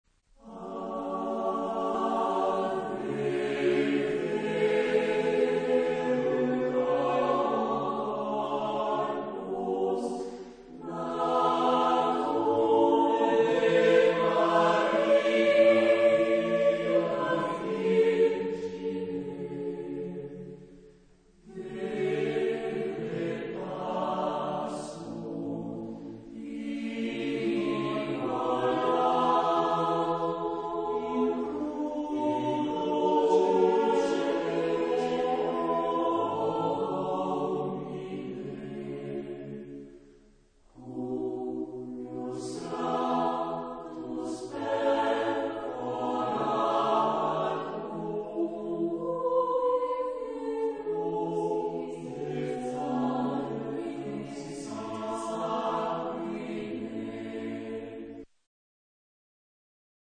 Genre-Style-Form: Sacred ; Motet
Type of Choir: TTBB  (4 men voices )
Tonality: C minor